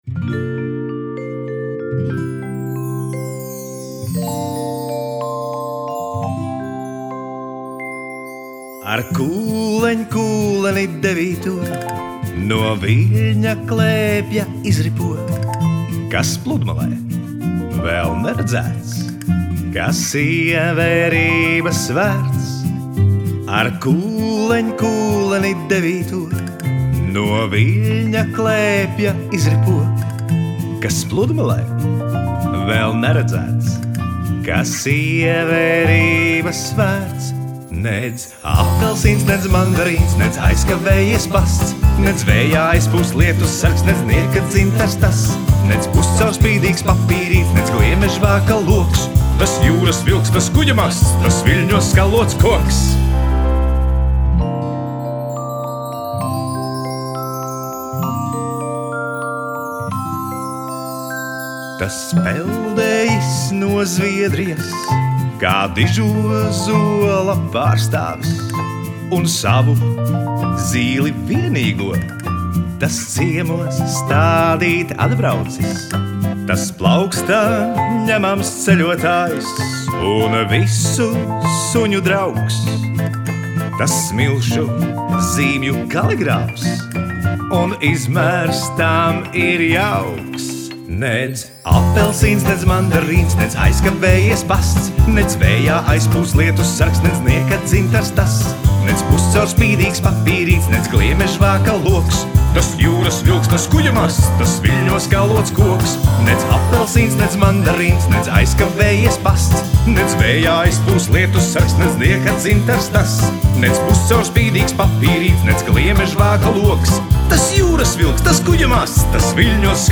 Dziesmas un dziesmu pavadījumi.
taustiņi
ģitāra
perkusijas.